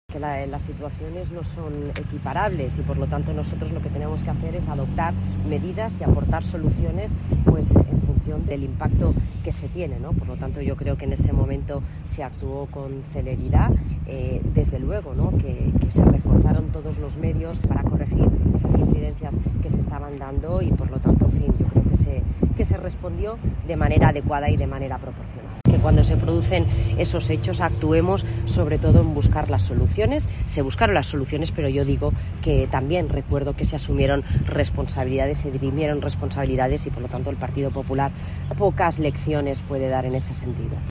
La ministra ha hecho estas declaraciones con motivo de su viosita a los terrenos para la construcción de Vivienda de Protección Autonómica (VPA) ubicada en Casa Plata, en Cáceres.